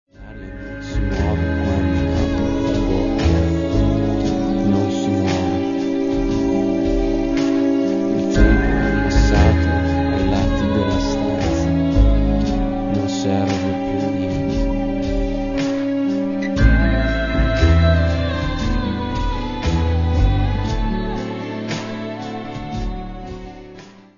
panorama di malinconia e vuoto esistenziale
Download un'anteprima di bassa qualità